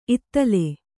♪ ittale